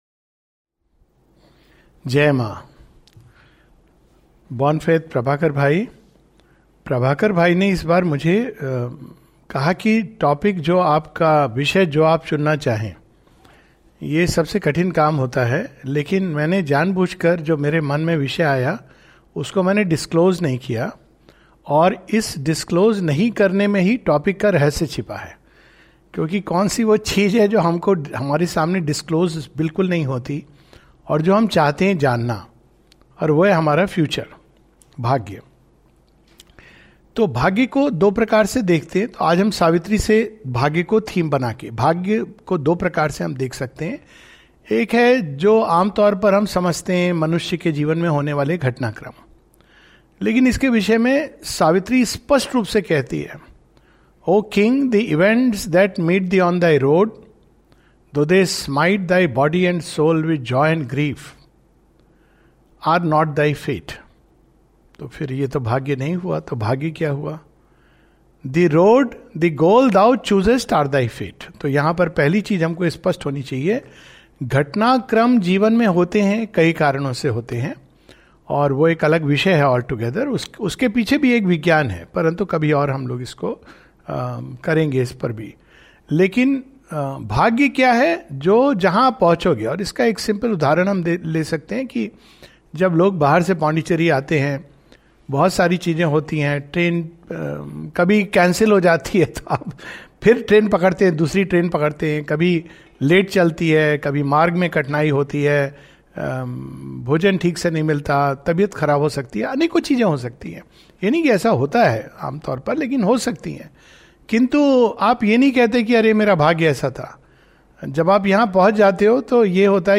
[Savitri and the Future of Humanity] This webinar with Savitri Study Circle, Bhubaneswar, moves around the future of humanity as revealed in Savitri.